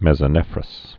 (mĕzə-nĕfrəs, -rŏs, mĕs-)